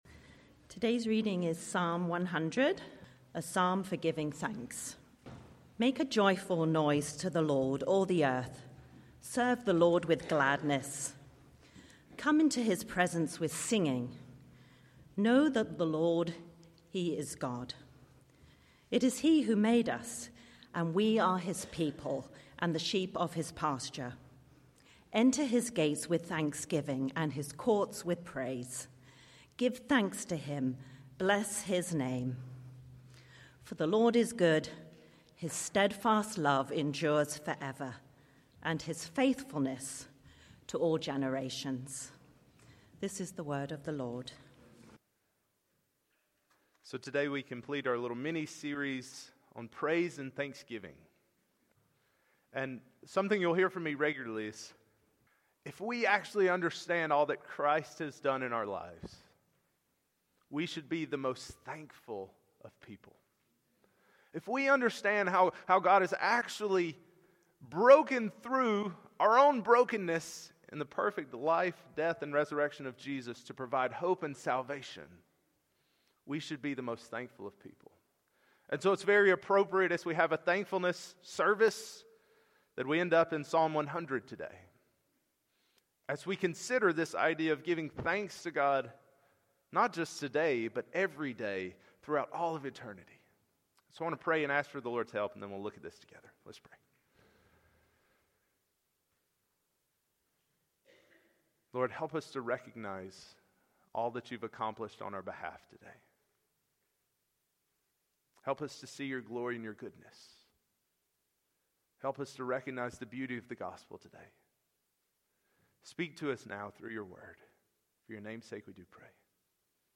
Passage: Psalm 100 Sermon